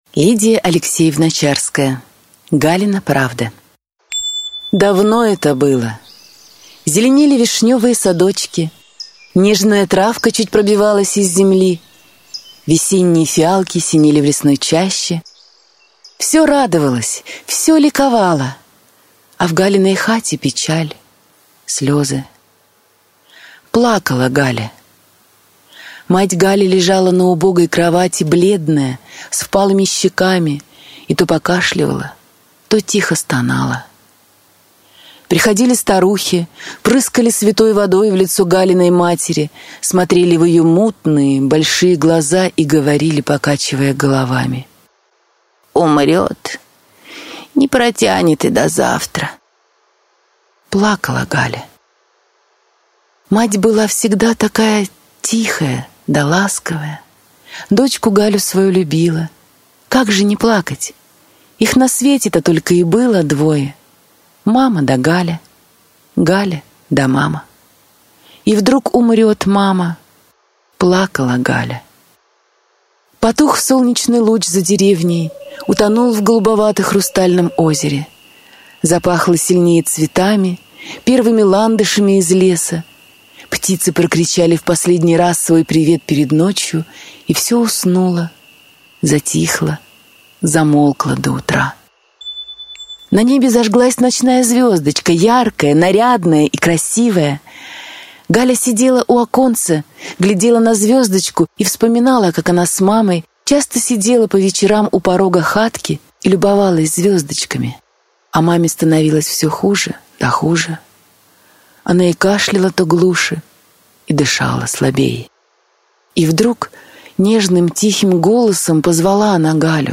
Аудиокнига Галина правда | Библиотека аудиокниг